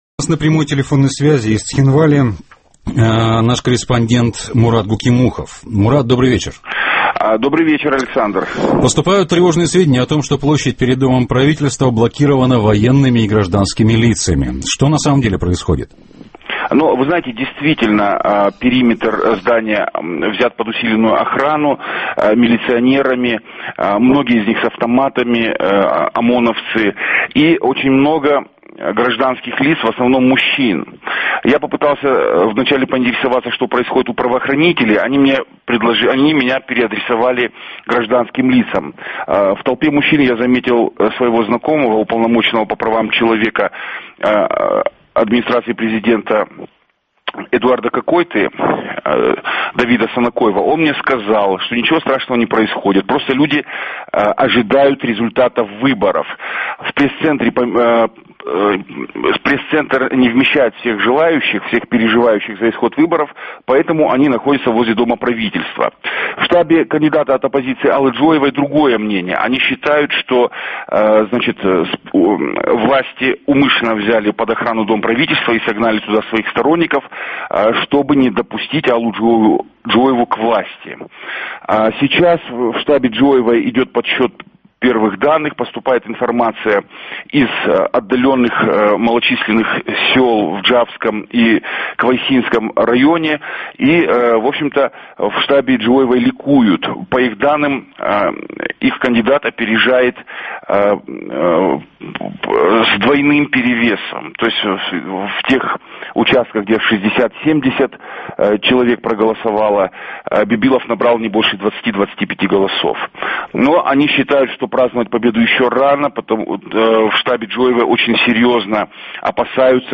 Наши корреспонденты передают из штабов кандидатов.